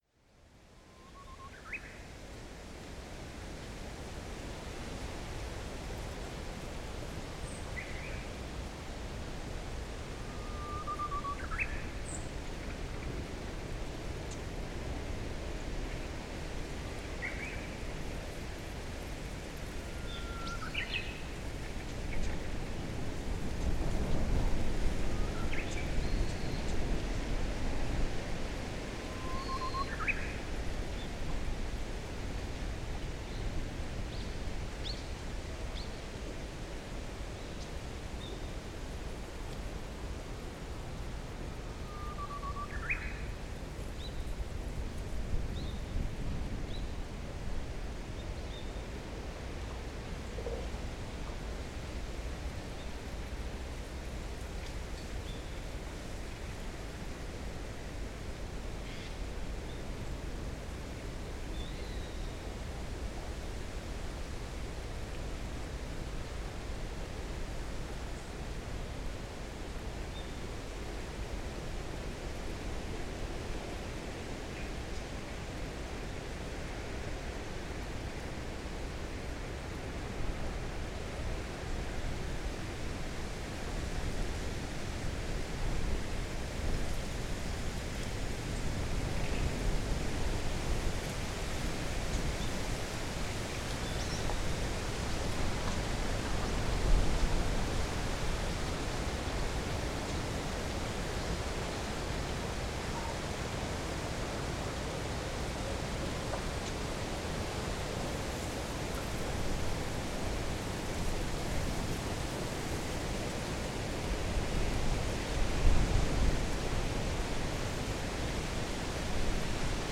ウグイス
ZOOM H6, RODE NT5 Pair（OMNI, Jecklin Disk type Stereo）2015年3月6日 千葉県
その時からウグイスのさえずりは始まっていた。鶴岡より少なくとも一月は早い。